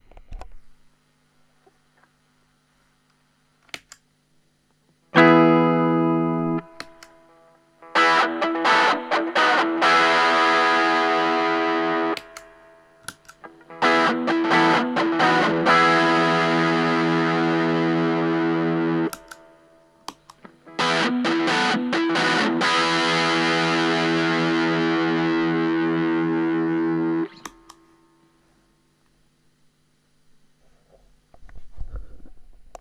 今回は下の方にあるように、アンプで鳴らしてマイク録音してます。
アンプはクリーンチャネルで、centaurもどき、SonicDrive、DynaRedの順に鳴らしてます。centaurもどきのみで歪みを作ると低音が少なく、ミッドレンジが強調されたようなサウンドで、そのままだと使いにくいかも知れません。
SonicDriveはまとまった聴きやすい音色、DynaRedはいかにもディストーションというバリバリした感じですね。